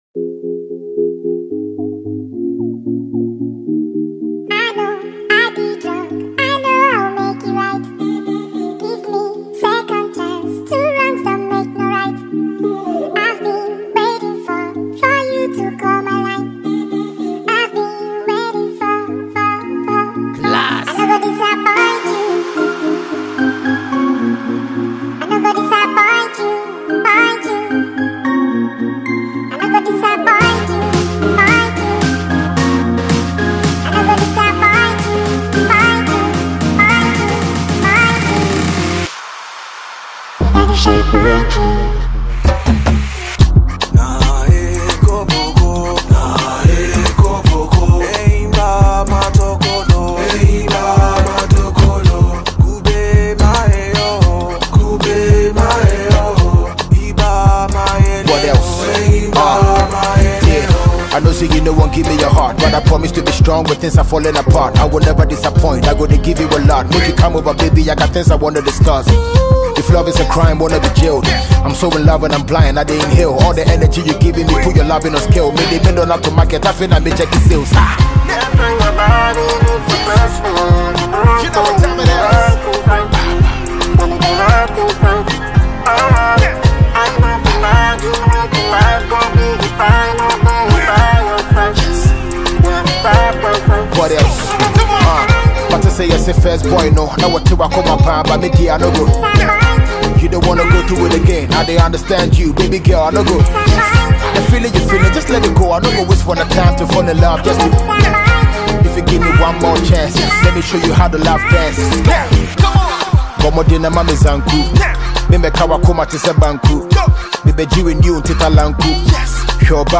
TrackRap